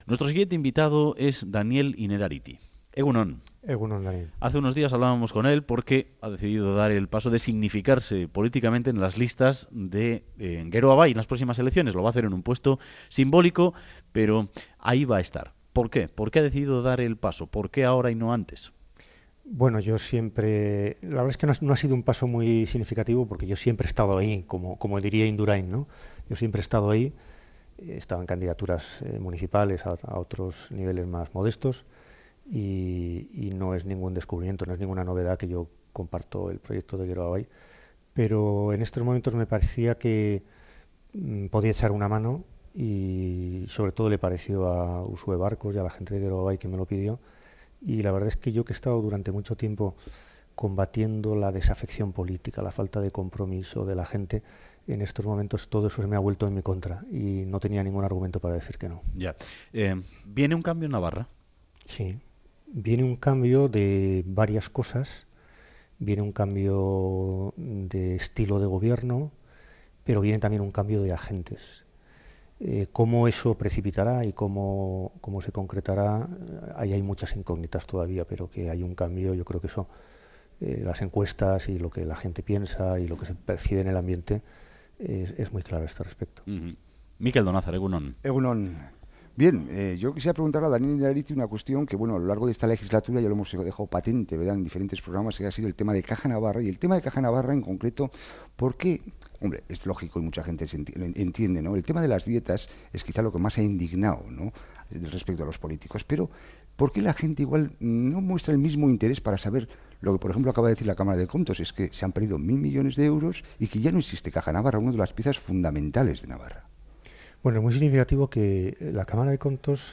Radio Euskadi BOULEVARD 'En Navarra viene cambio de estilo de gobierno y de agentes políticos' Última actualización: 26/03/2015 10:32 (UTC+1) En entrevista al Boulevard de Radio Euskadi, el filósofo e integrante de las listas electorales de Geroa Bai, Daniel Innerarity, ha afirmado que en Navarra viene un cambio de estilo de gobierno y de agentes políticos. Ha explicado que su presencia en listas puede parecer novedosa, pero que él "siempre ha estado ahí", colaborando, y que era el paso lógico.